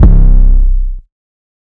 18 - Big Dro 808.wav